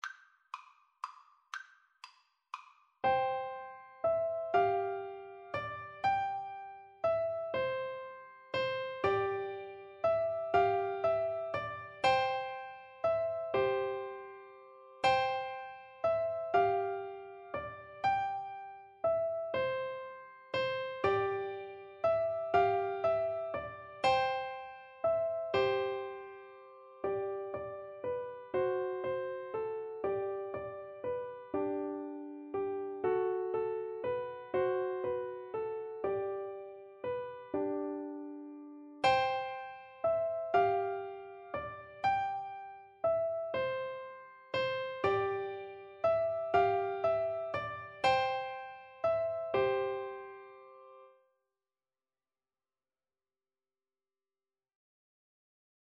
Traditional Trad. Girls and Boys Come Out to Play (English Traditional) Piano Four Hands (Piano Duet) version
3/4 (View more 3/4 Music)
C major (Sounding Pitch) (View more C major Music for Piano Duet )
Brightly = c. 120